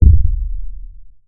effect__giant_step.wav